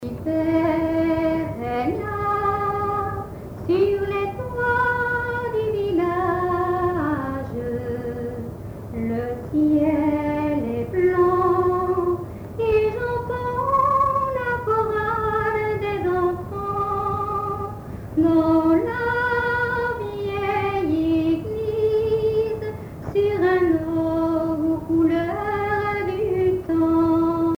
Genre strophique
chansons populaires
Pièce musicale inédite